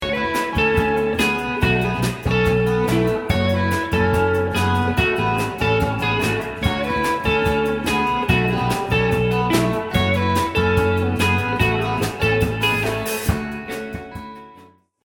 live at the Revolution Cafe.